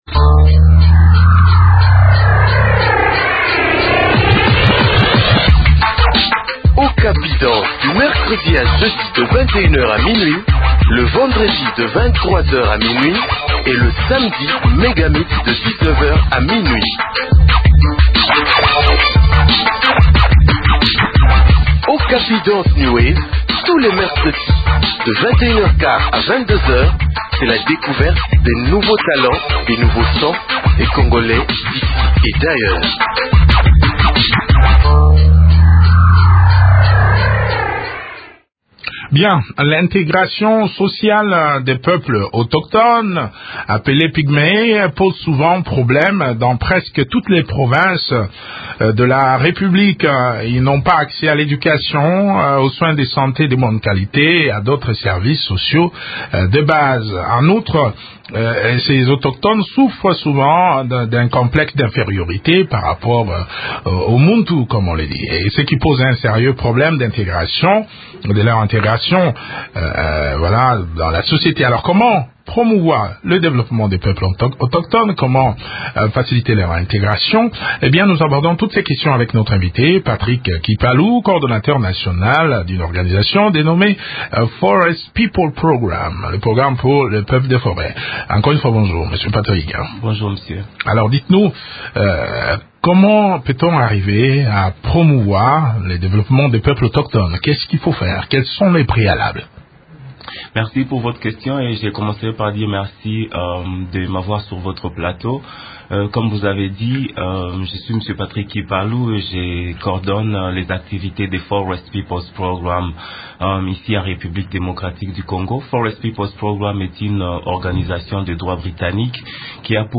répond aux questions des auditeurs